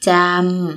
– jamm